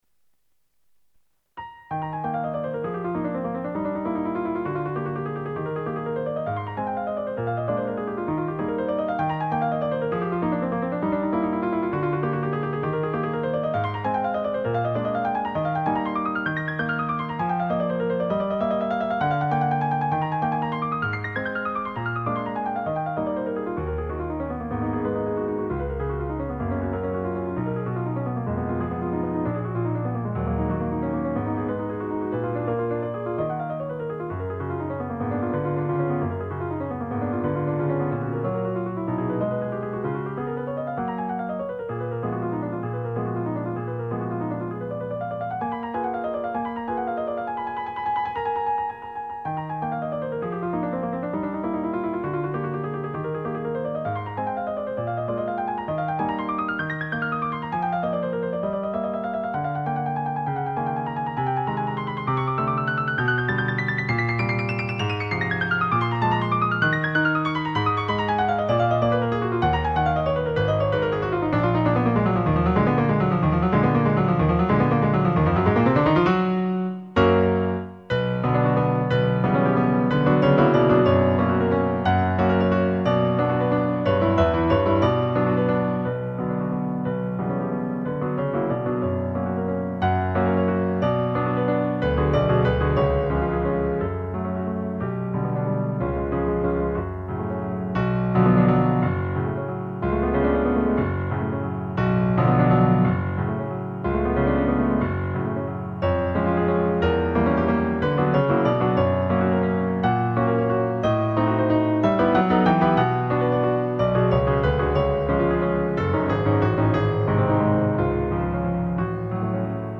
コロコロ軽快に駆け巡る速めの３連符の連続、徐々に重厚感を増していく旋律の変化、要所要所で思い出したように繰り返す冒頭の旋律。
冒頭部は、軽やかに駆け抜ける変ホ長調の流暢な音階。
中間部は、左手の和音で重厚感を増す、変動の短調部。
そして壮大のコーダ、右手の３連符は常に単音でありながら、豪華さと勢いを増していきます。